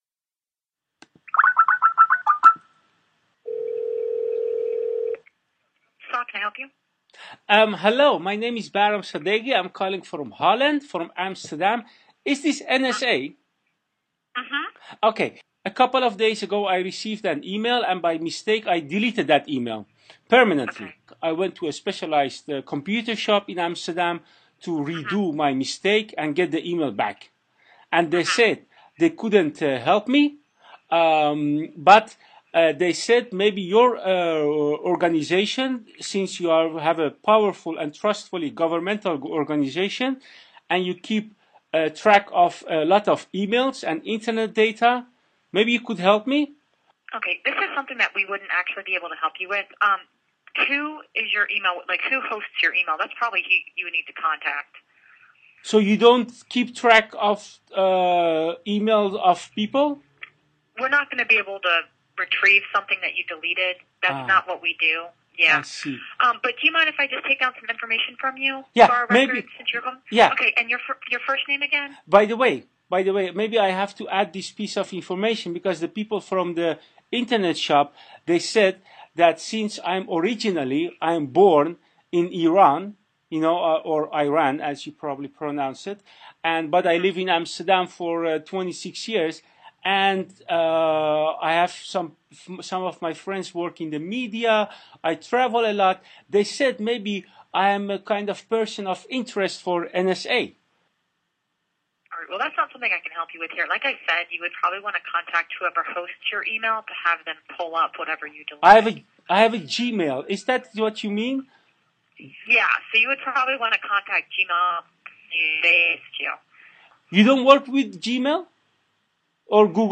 Also, a collage of statements from Dir. National Intelligence James Clapper, including statements made in Congress, that seem to be of a, uh, theatrical nature.